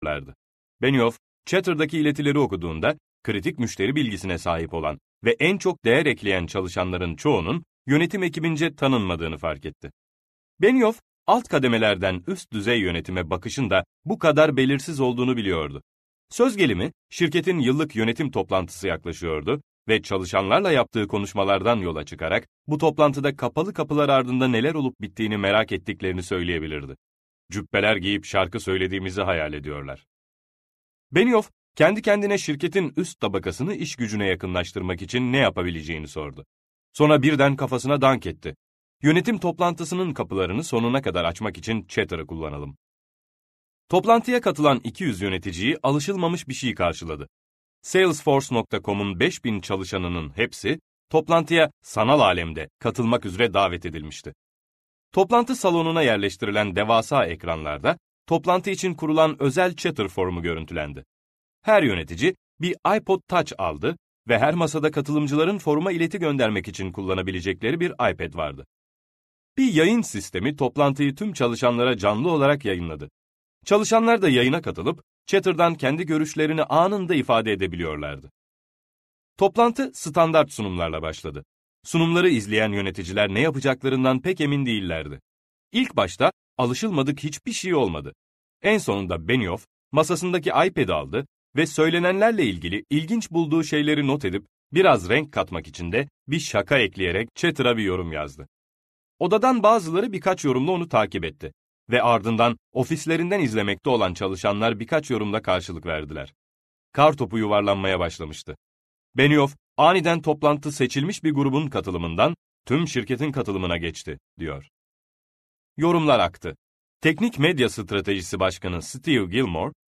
İşbirlikçi Bir Lider Misiniz - Seslenen Kitap